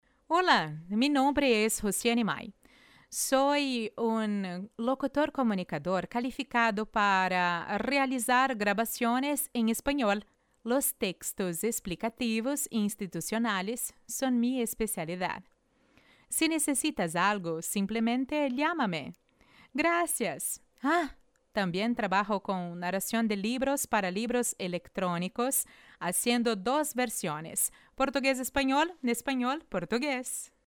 Demo Bilingue :
VOZ BEM MACIA E SUAVE!!!